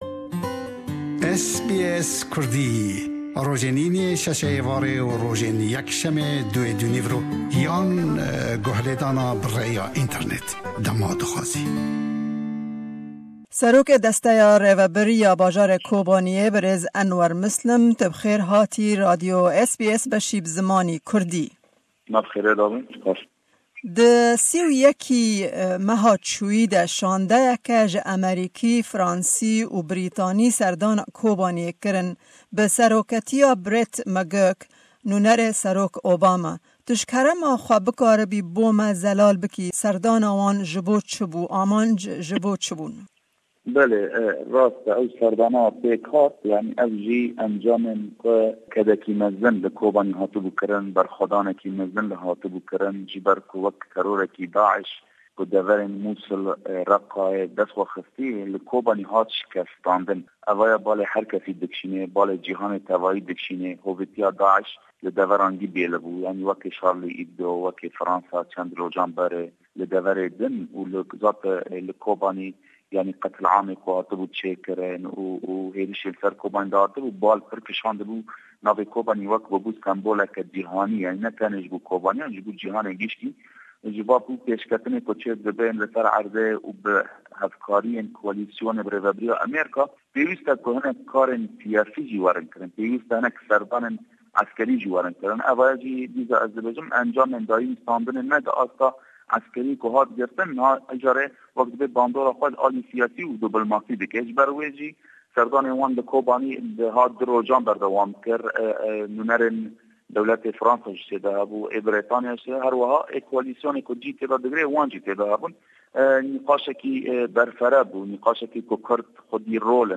Me hevpeyvînek bi serokê desteya rêberbir ya Kobaniyê berêz Anwer Mislim re pêk anî. Hevpeyvîn sebaret bi serdana shandeya Amerîkî, Brîtanî û Firensî bo Kobaniyê ye. Me ji berêz Mislim li ser sedemên serdana vê shandeyê pirsî û herweha li ser rewsha Kobaniyê bi gîshtî jî pirsî.